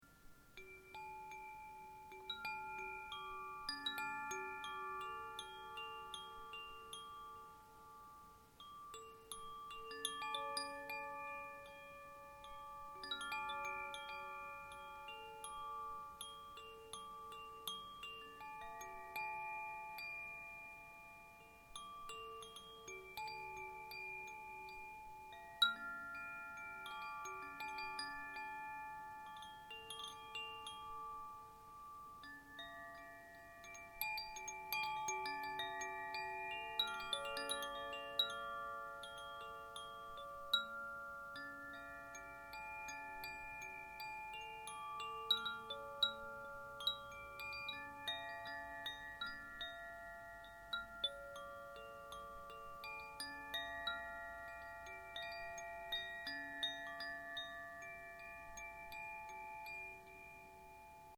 Precise tuning creates a play of clear tones that is rich in overtones. The overtones of the shorter chords gradually dominate and become fundamentals, thus forming a circular tone range.
Zaphir chimes come in several tunings, each with its specific magic timbre, and in seven basic colours with a choice of different shades.
Move the chime gently holding it by its cord: the crystalline, relaxing sound may leave you in quiet wonder.
5 wind chimes, each has its own sound.